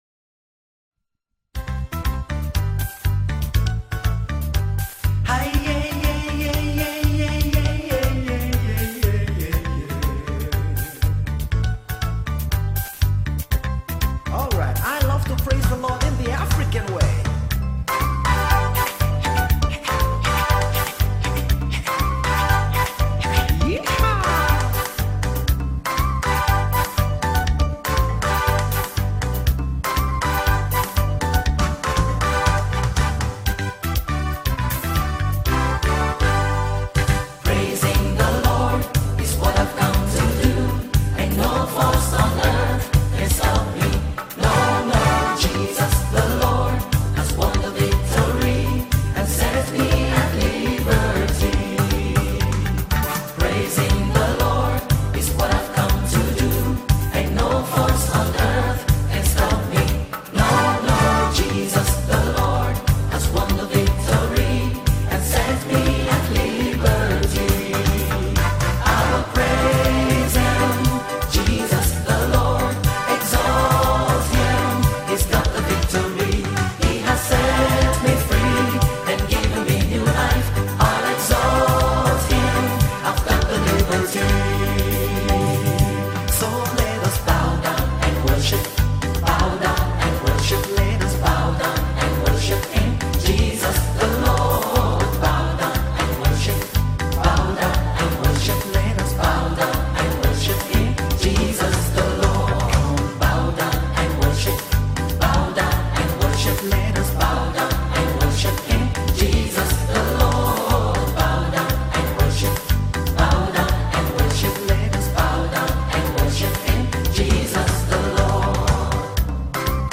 is a Nigerian gospel singer